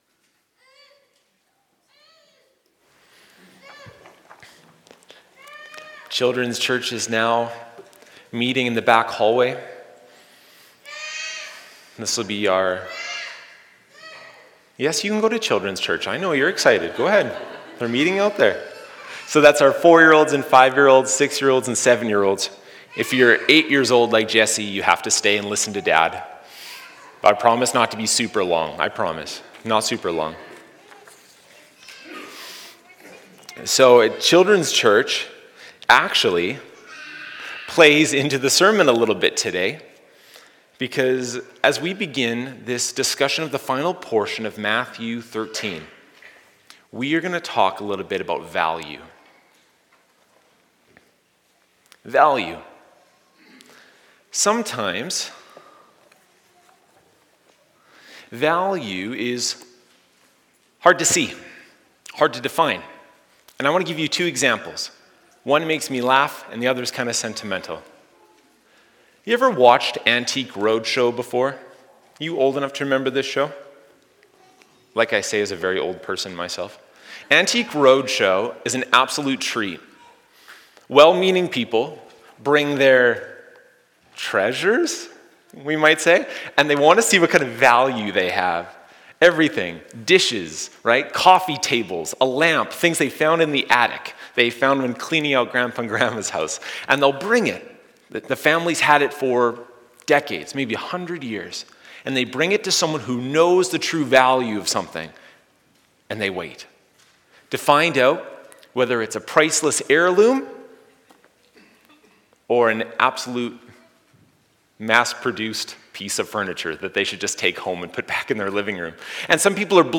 Sermons | Bridgeway Community Church